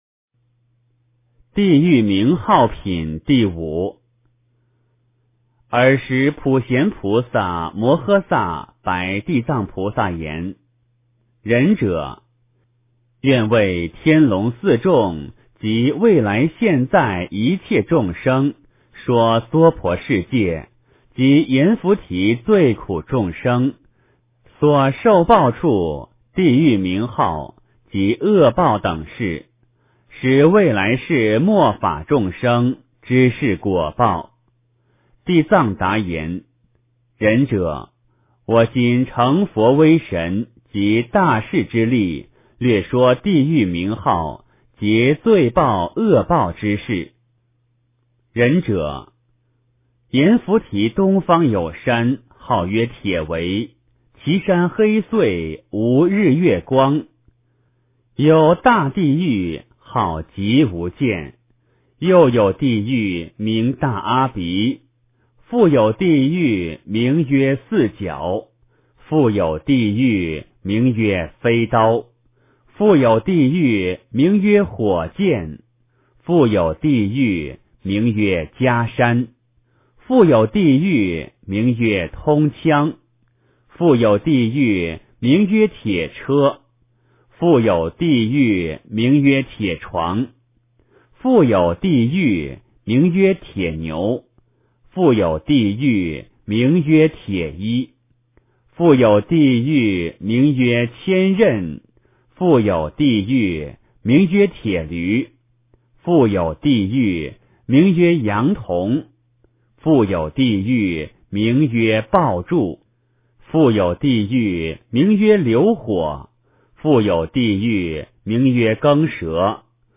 地藏经-地狱名号品第五 诵经 地藏经-地狱名号品第五--佛经 点我： 标签: 佛音 诵经 佛教音乐 返回列表 上一篇： 地藏经-分身集会品第二 下一篇： 八十八佛大忏悔文 相关文章 貧僧有話2說：我对金钱取舍的态度--释星云 貧僧有話2說：我对金钱取舍的态度--释星云...